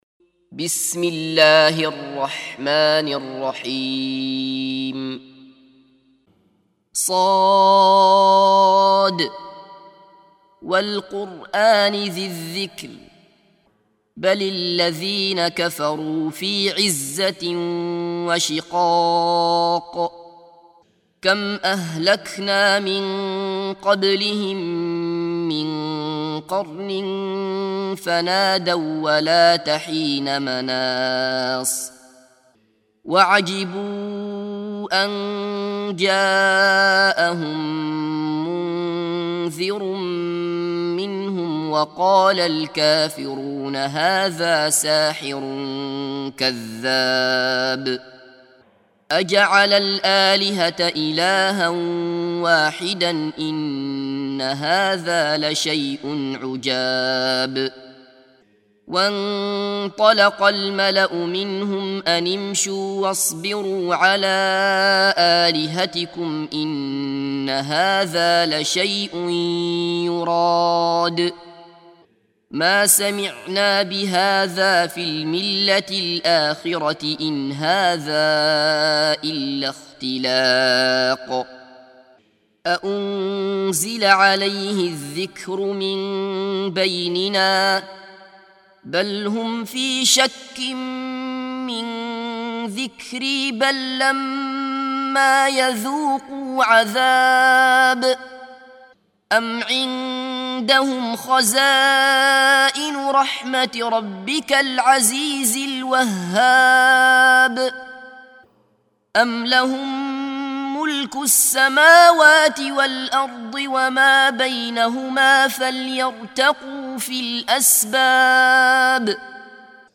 سُورَةُ ص بصوت الشيخ عبدالله بصفر